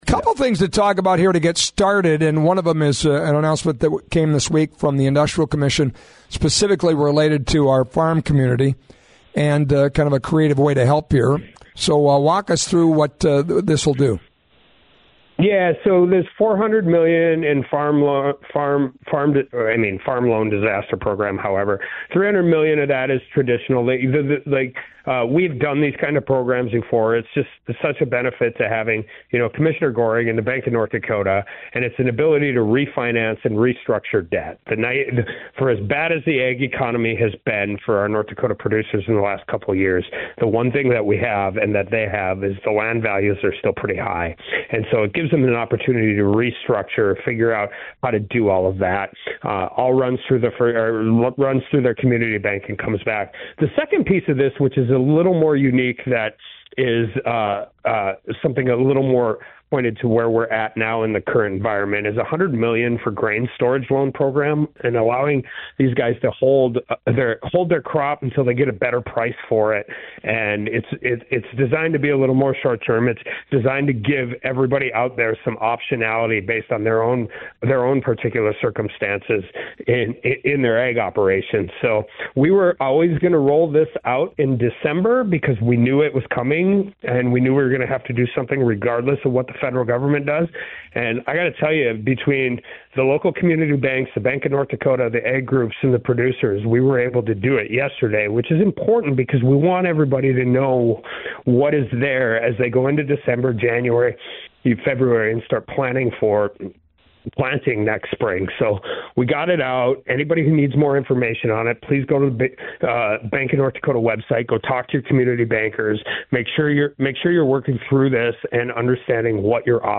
Governor Kelly Armstrong discusses North Dakota’s Farm Financial Stability Loan Program